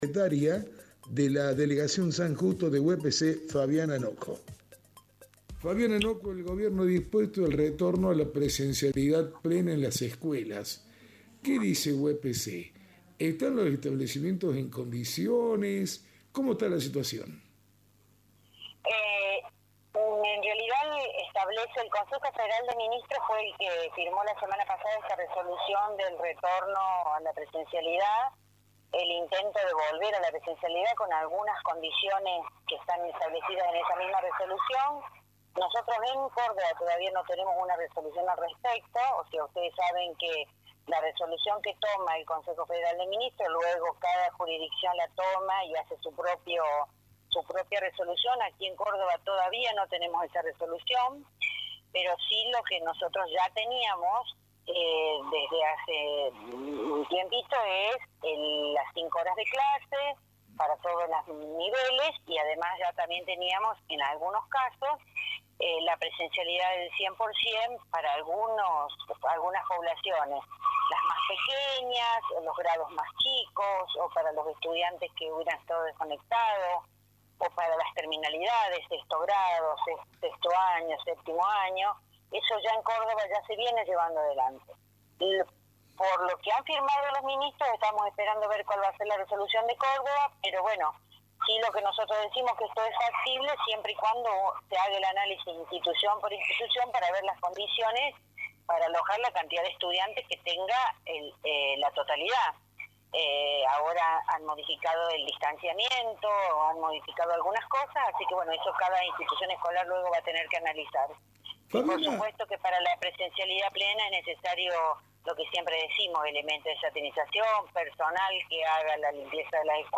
habló